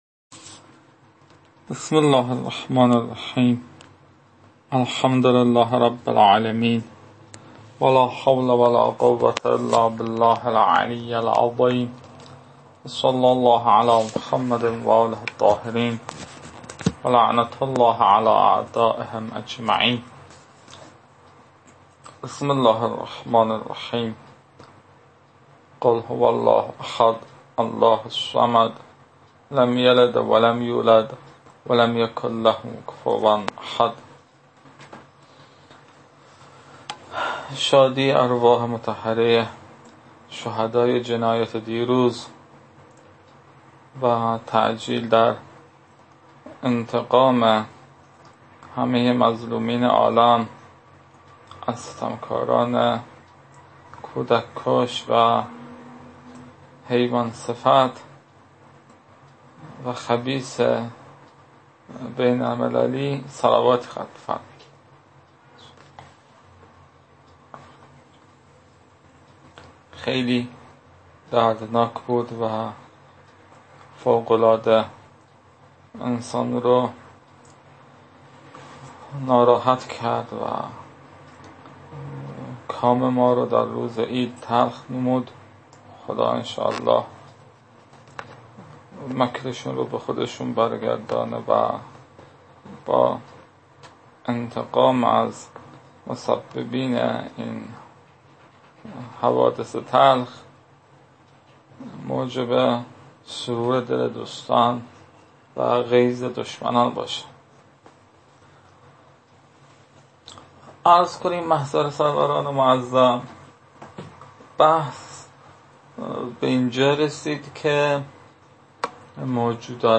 تدریس رساله اول